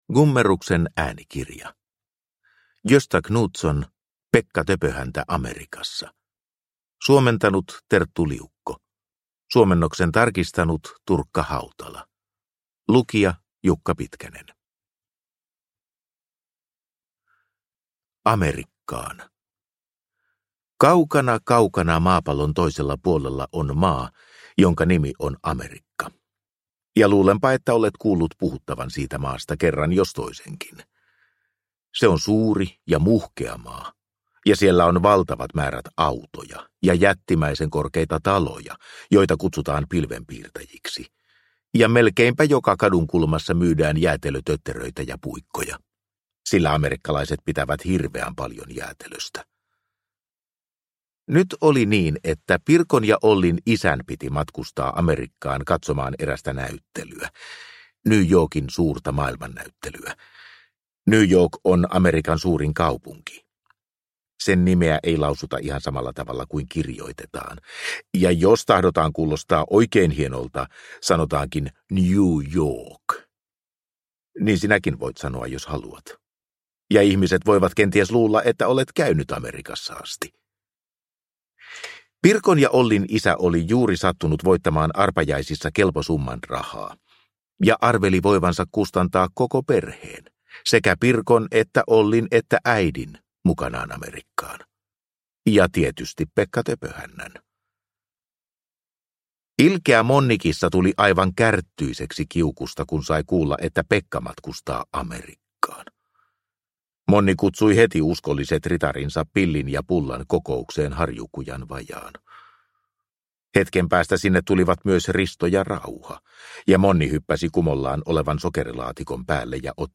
Pekka Töpöhäntä Amerikassa – Ljudbok – Laddas ner